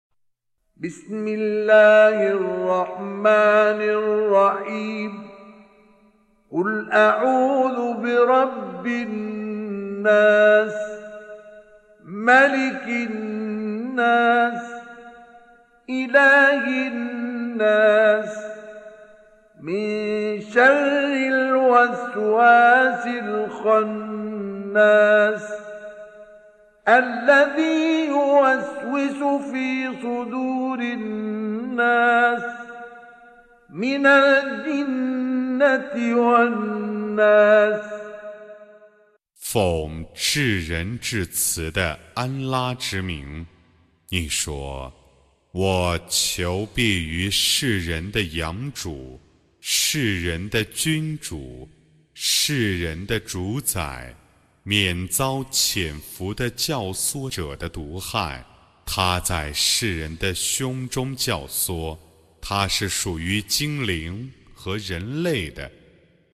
With Qari Mustafa Ismail